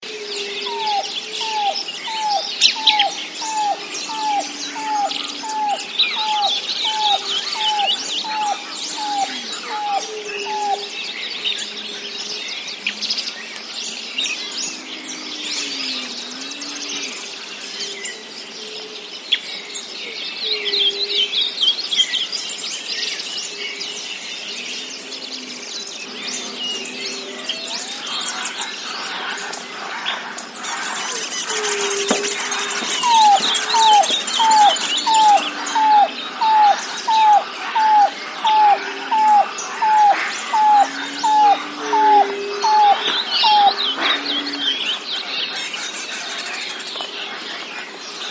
Ash-collored Cuckoo Argentina bird